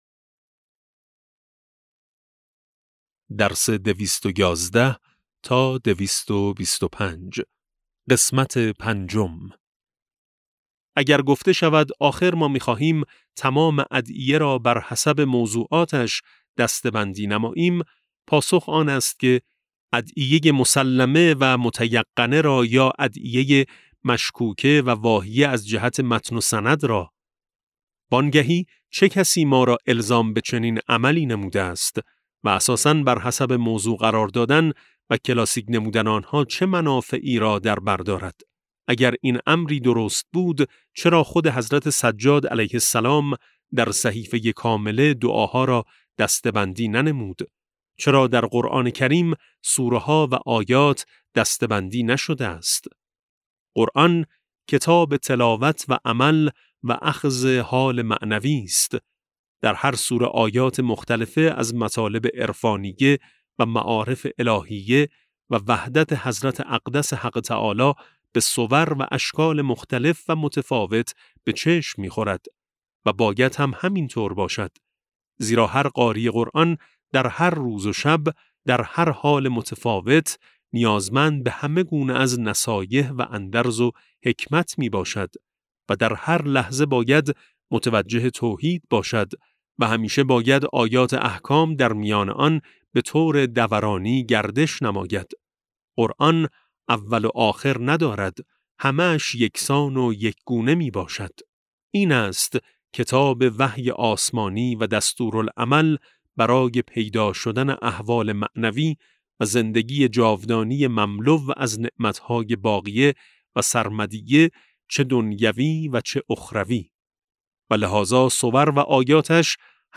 کتاب صوتی امام شناسی ج15 - جلسه5